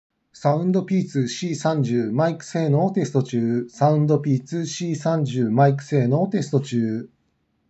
マイク性能は中の上
✅SOUNDPEATS C30のマイクテスト
わたしの声の低さを差し引いてもしっかり聞こえます。多少低音にひっぱられることは覚悟していましたが思ったよりはマシでした。